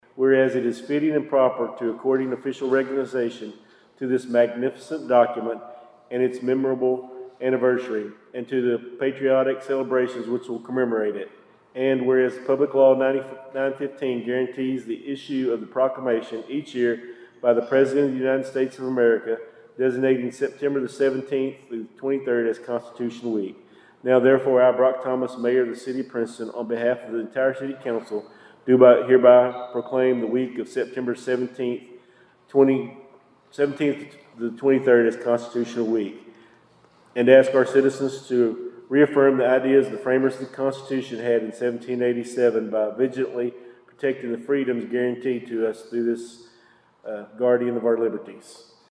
In other new business, Mayor Thomas declared the week of September 17th as Constitution Week, commemorating the 238th anniversary of the United States Constitution.